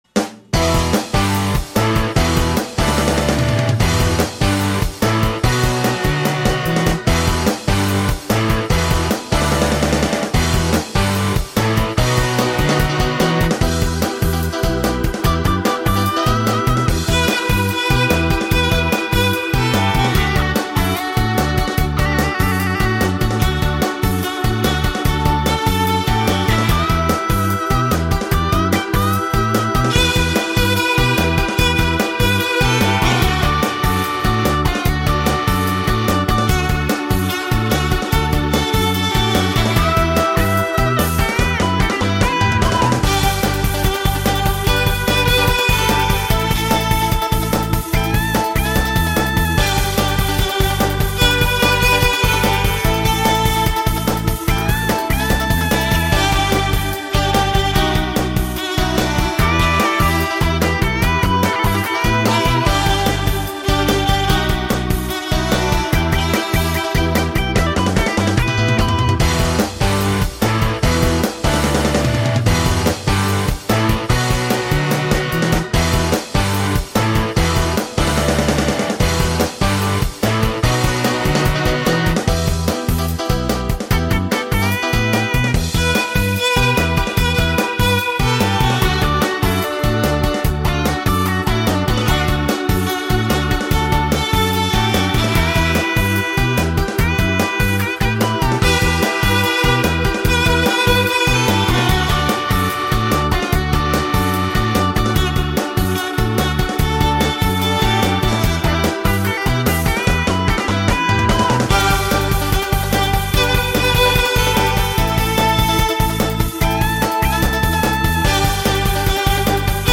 바이올린